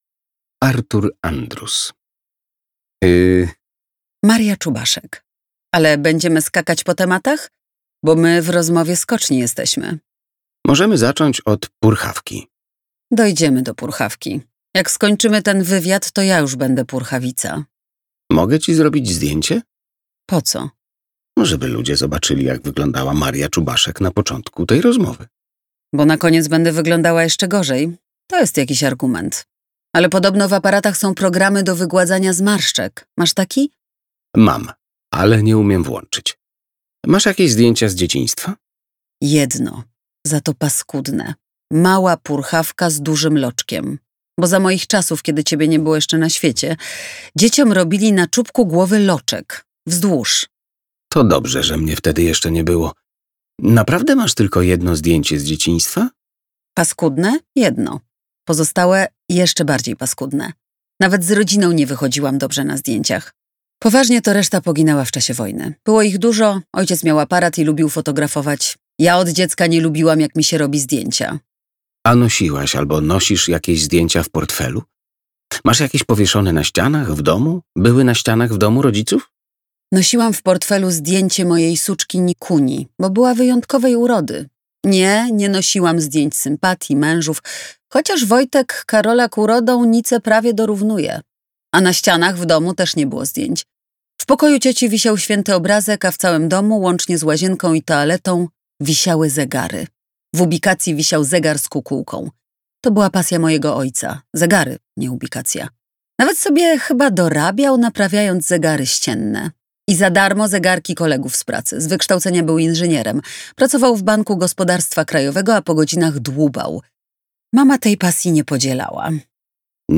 Każdy szczyt ma swój Czubaszek - Artur Andrus, Maria Czubaszek - audiobook